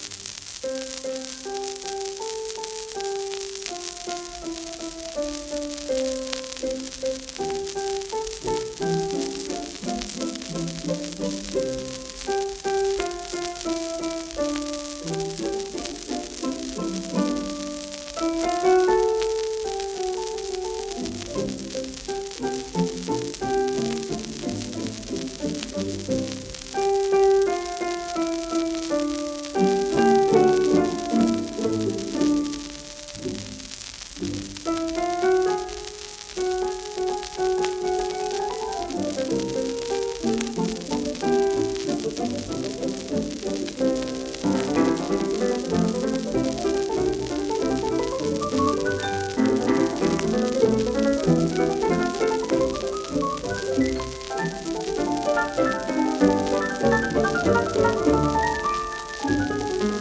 1931年録音　自作自演